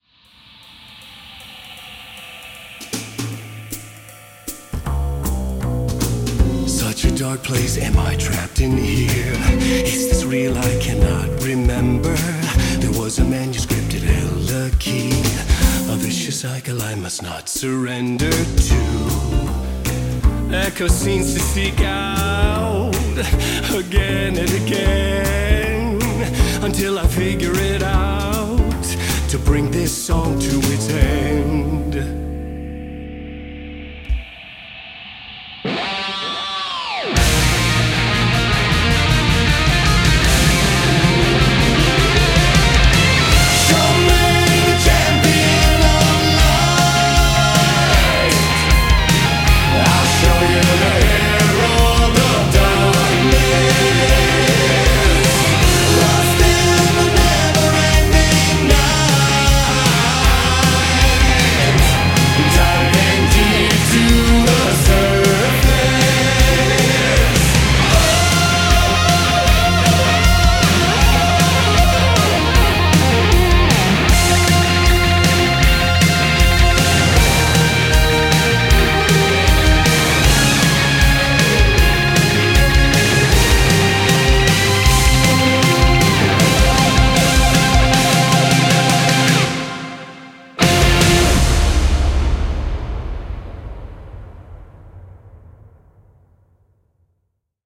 BPM156-156
Audio QualityPerfect (Low Quality)